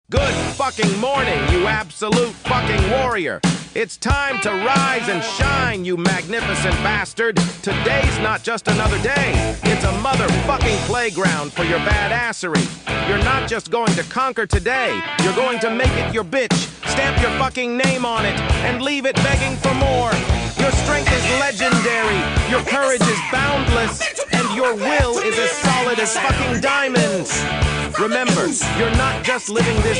An Energetic Greeting for Every New Day
with a modern and confident style.
Ringtone